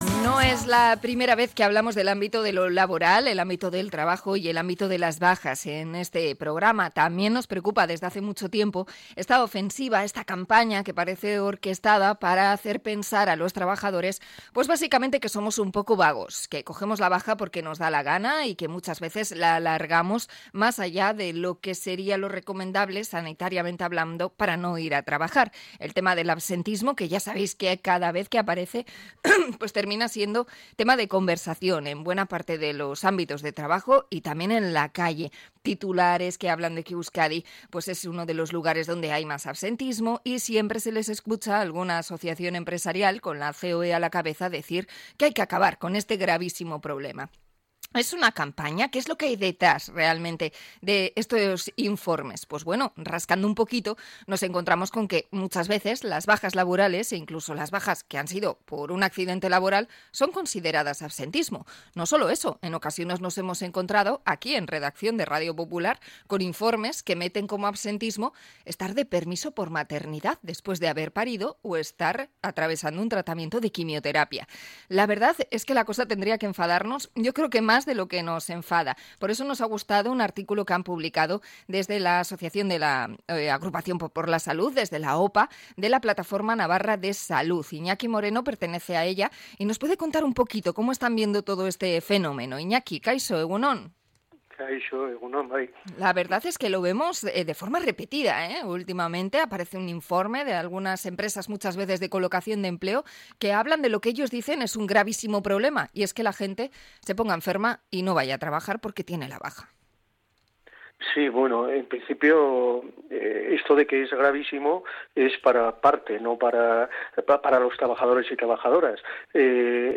Entrevista a la Plataforma Navarra de Salud sobre la campaña contra las bajas laborales